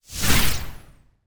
Free Frost Mage - SFX
Ice_casting_58.wav